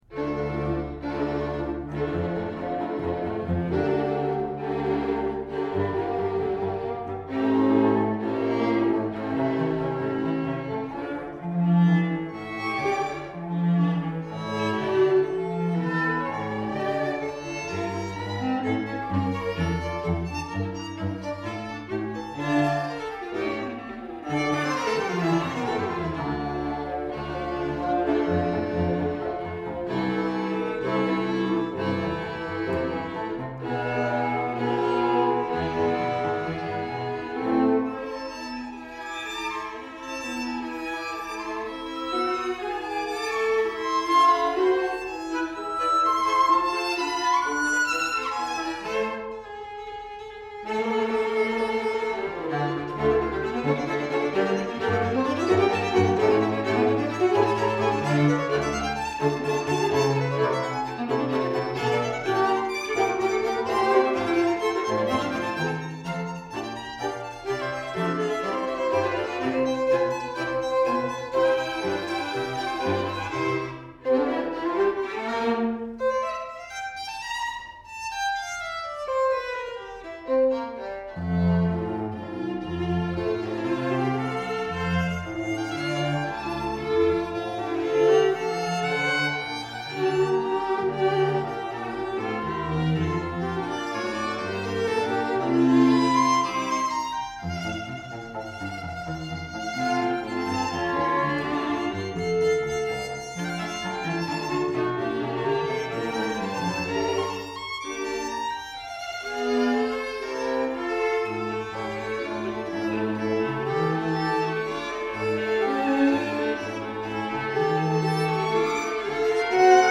Soundbite 1st Movt
For 2 Violins, 2 Violas and Violoncello